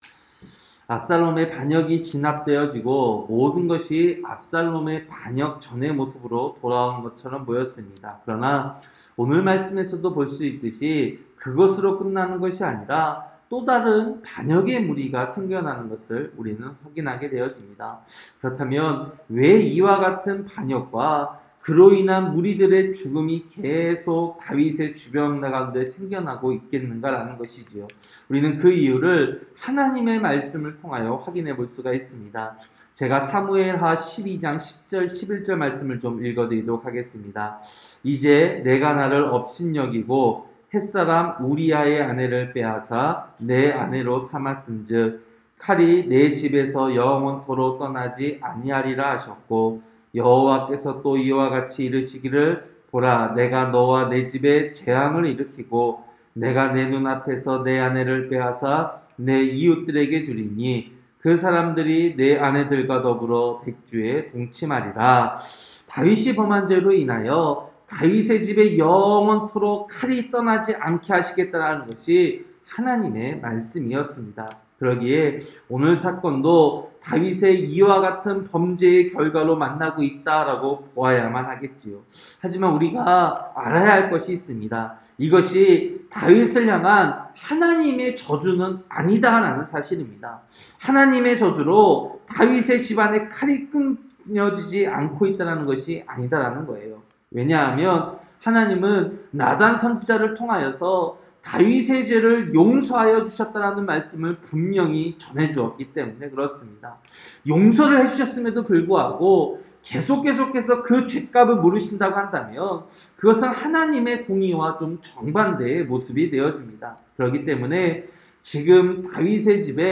새벽설교-사무엘하 20장